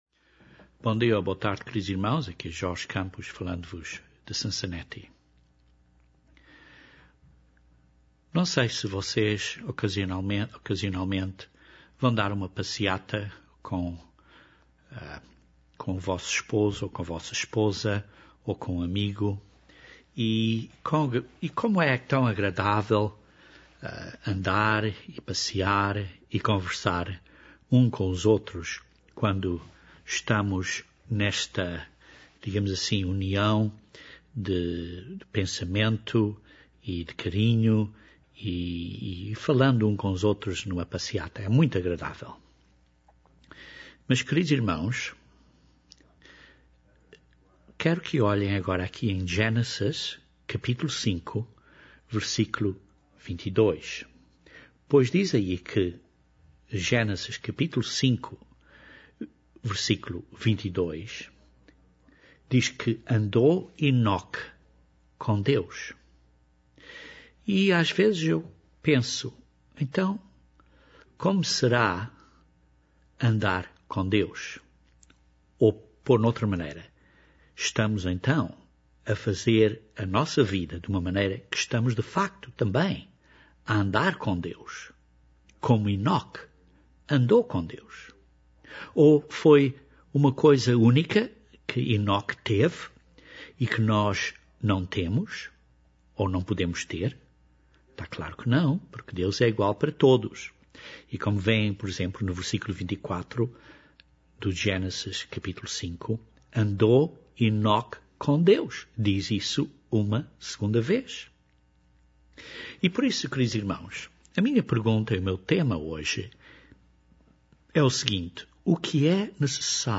Este sermão ajuda-nos a perguntar a nós mesmos se estamos a andar com Deus.